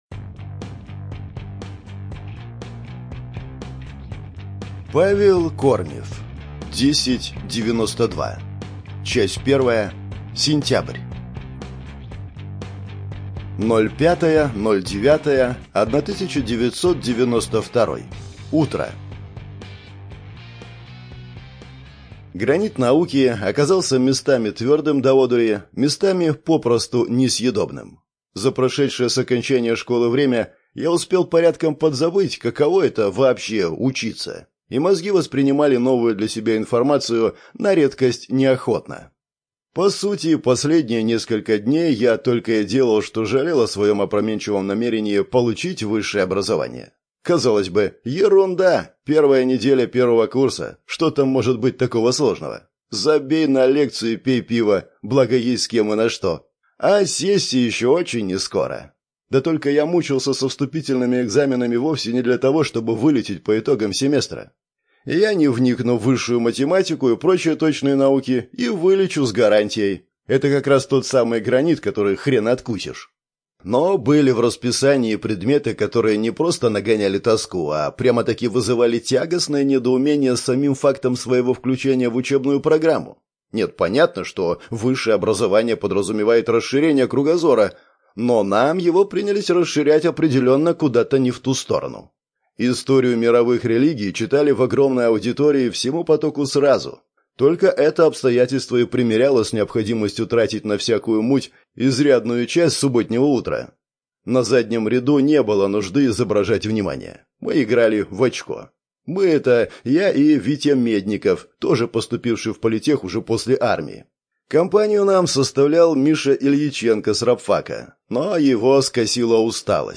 Внимание! Содержит нецензурную брань.